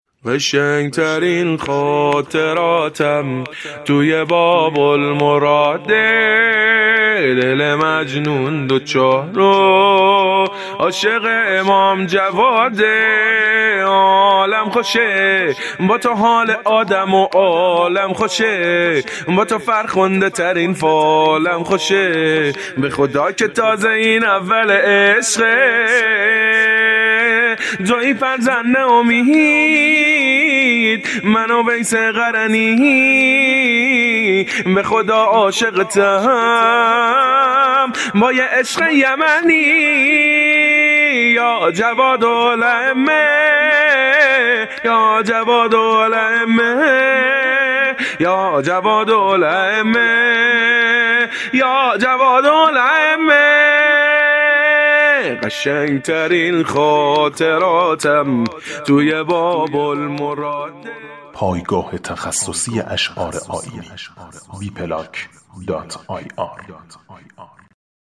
سرود احسان جاودان